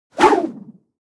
GrappleBoost.wav